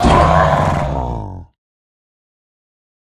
Minecraft Version Minecraft Version 25w18a Latest Release | Latest Snapshot 25w18a / assets / minecraft / sounds / mob / ravager / death2.ogg Compare With Compare With Latest Release | Latest Snapshot
death2.ogg